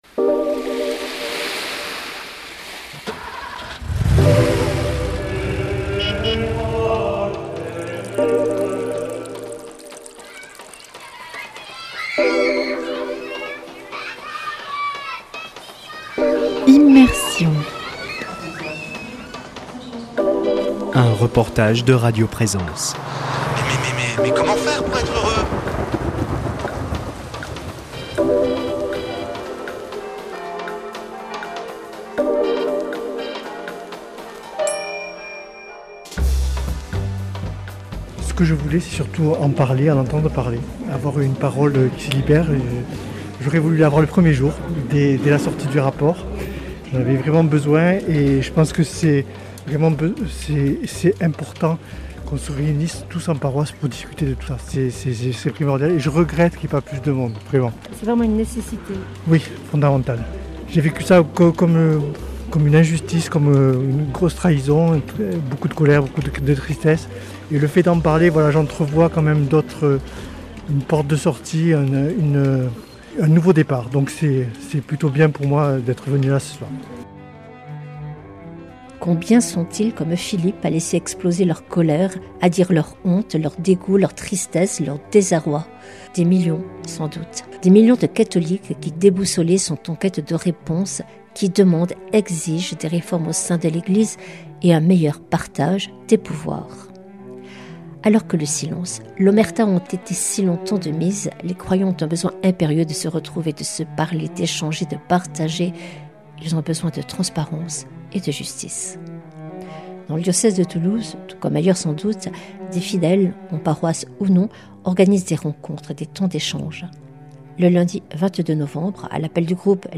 Le lundi 22 novembre à l’appel du groupe les 7 pour faire du 9 une quinzaine de personnes se retrouvaient dans ce que l’on nomme un Tiers lieu, à Toulouse une des salles de l’église du Sacre Cœur.